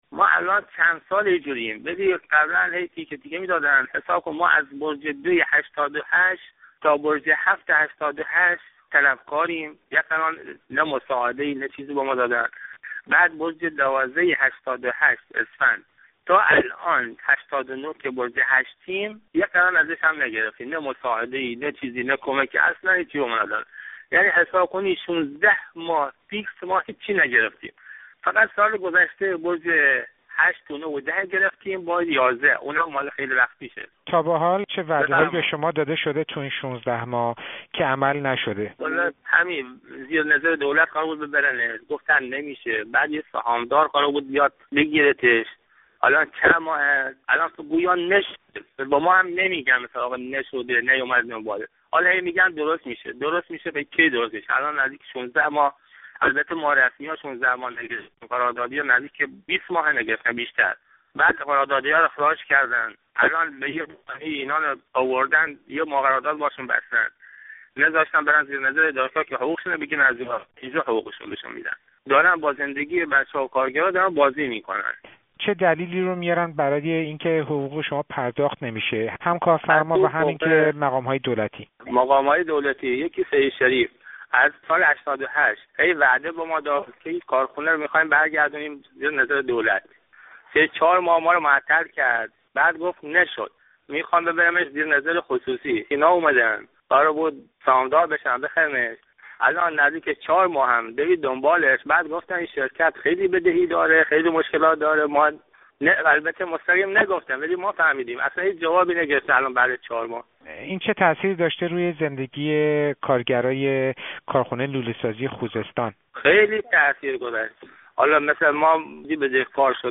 گفت‌وگو با یکی از کارگران کارخانه لوله‌سازی خوزستان